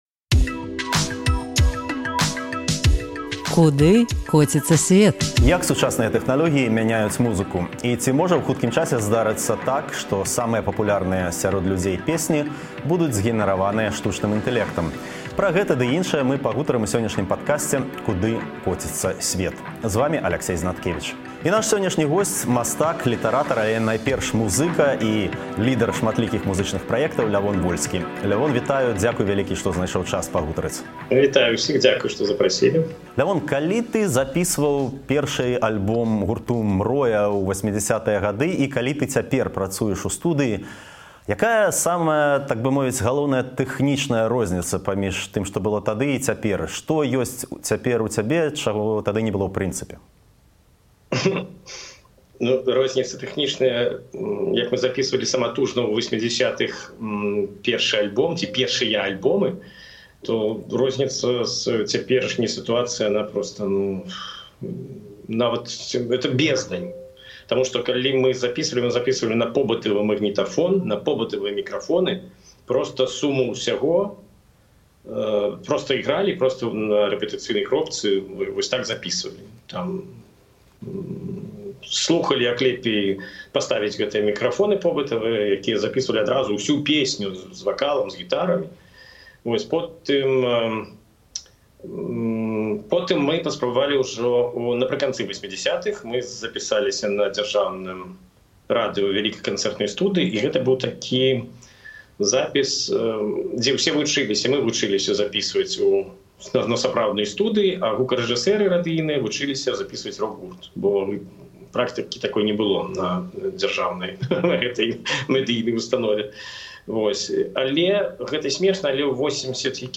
Пра гэта ў падкасьце «Куды коціцца сьвет» -- размова зь мастаком, літаратарам, але найперш музыкам і лідэрам шматлікіх музычных праектаў Лявонам Вольскім.